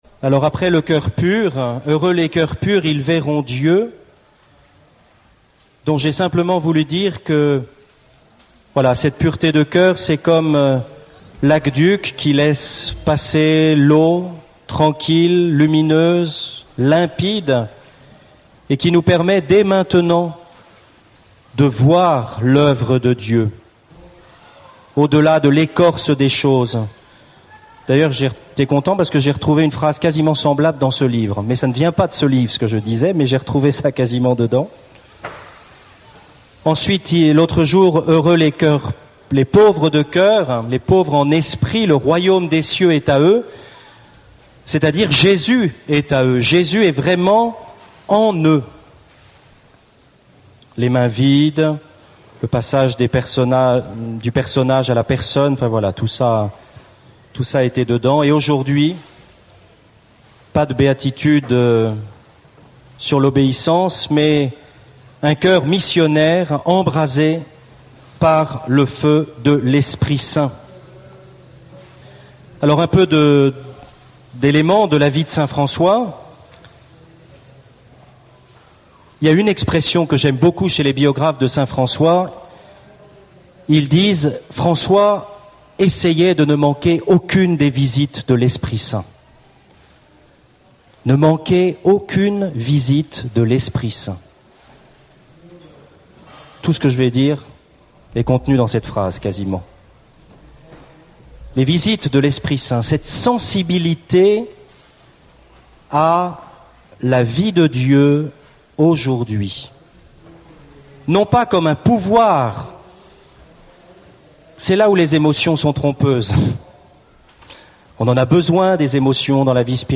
Enseignement
Session famille 3 (du 4 au 9 août 2012)